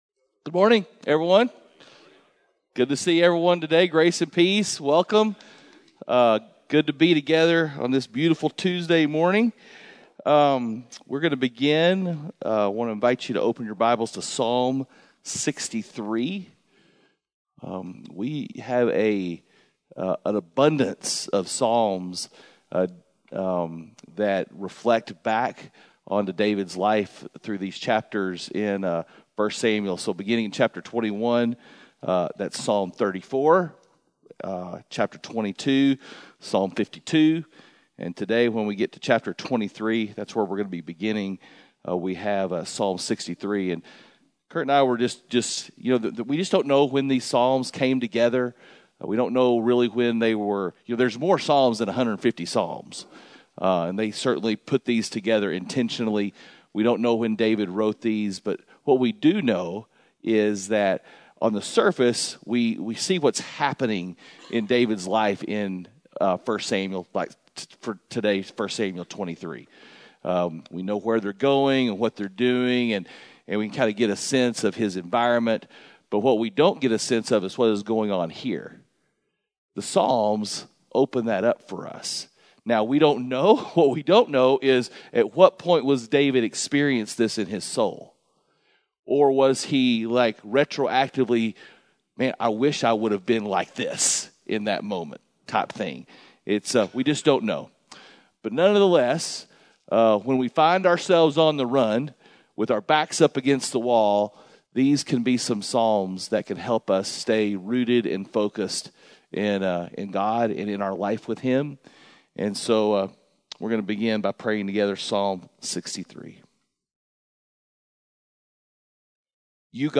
Men’s Breakfast Bible Study 5/4/21
Mens-Breakfast-Bible-Study-5_4_21.mp3